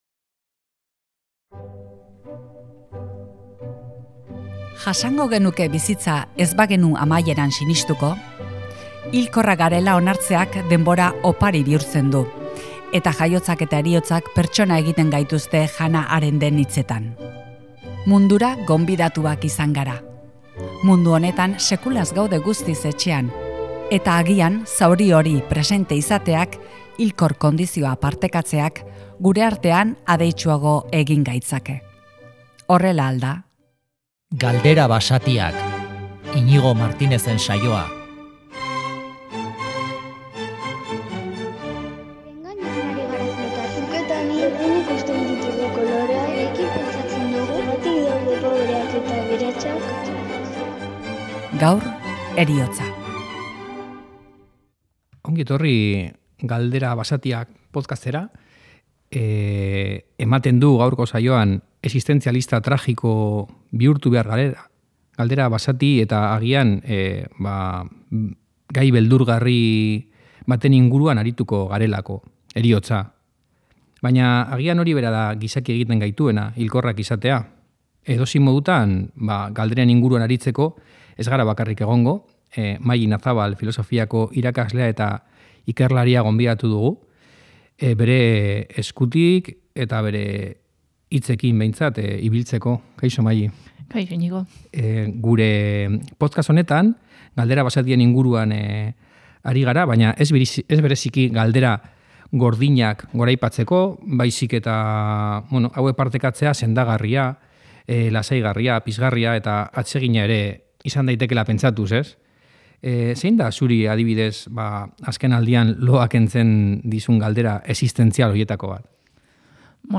astero gonbidatu batekin tertulian aritzen da